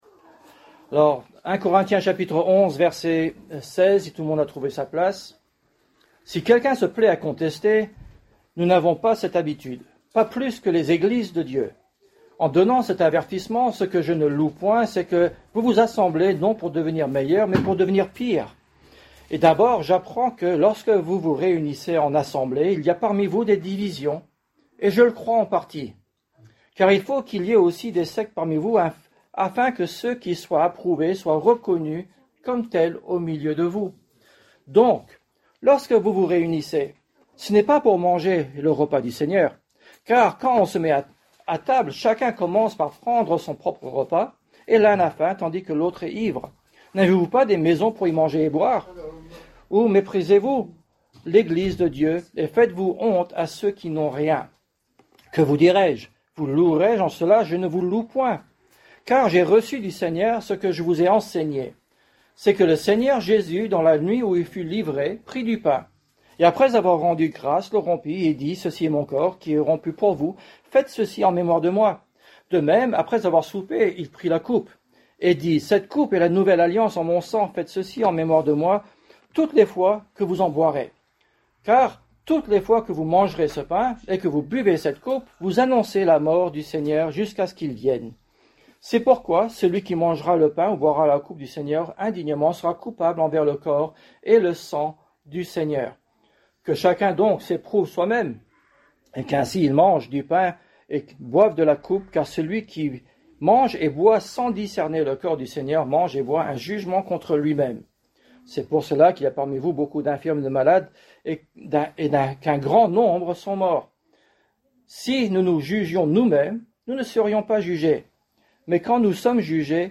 Genre: Prédication